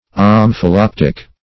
Search Result for " omphaloptic" : The Collaborative International Dictionary of English v.0.48: Omphalopter \Om`pha*lop"ter\, Omphaloptic \Om`pha*lop"tic\, n. [Gr.